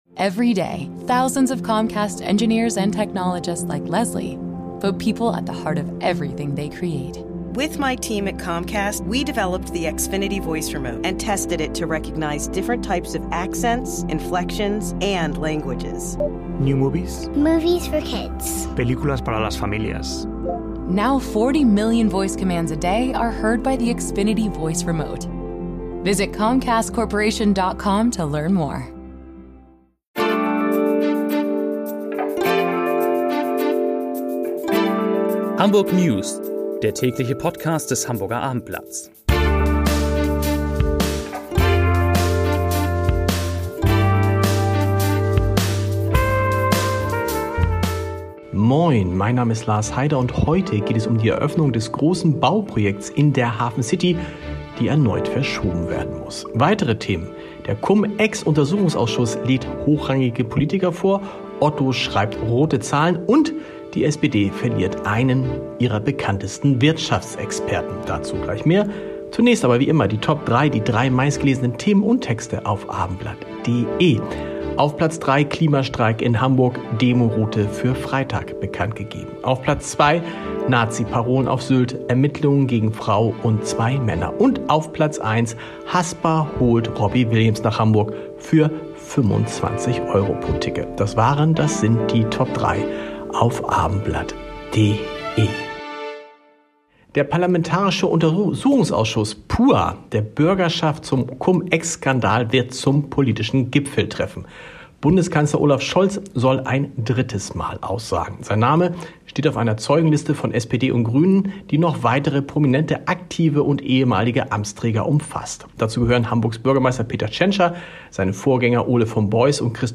Hamburg-News: Westfield-Eröffnung muss erneut verschoben werden ~ Hamburg-News – der aktuelle Nachrichten-Überblick um 17 h Podcast